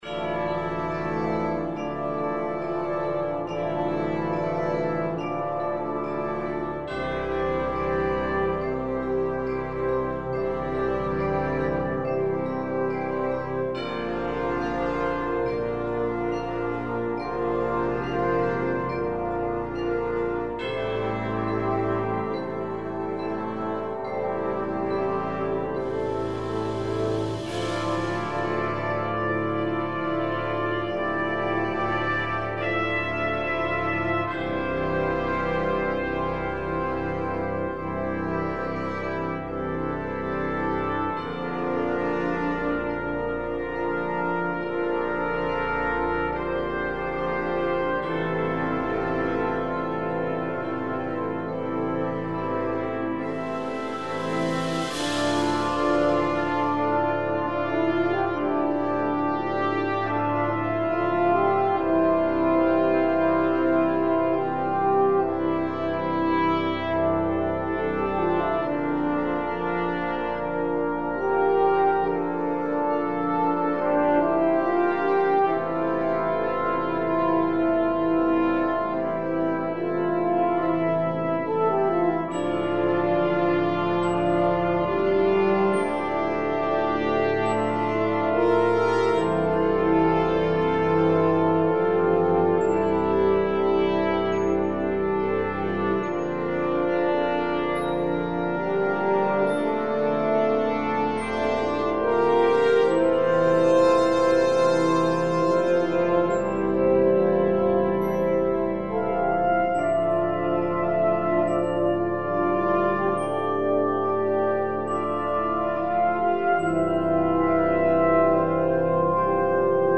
Brass Band (with featured Quintet)
A reflective and meditative reimagining of the hymn tune